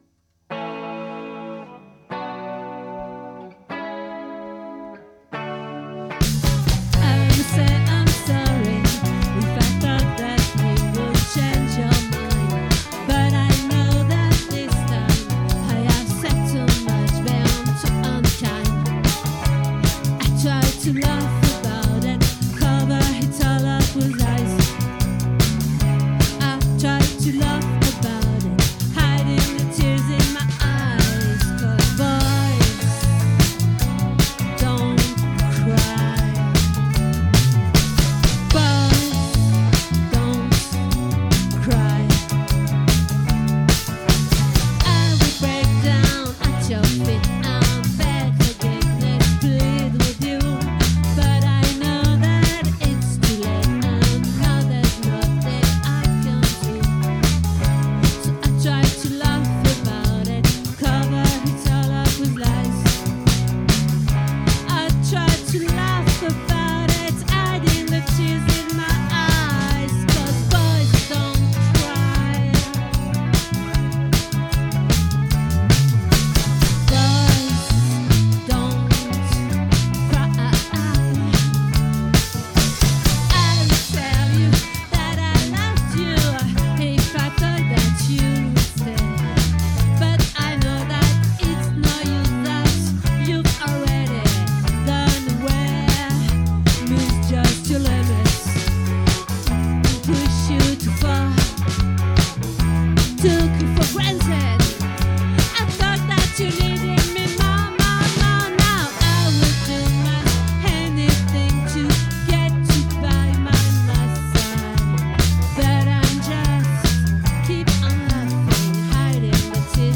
🏠 Accueil Repetitions Records_2025_12_22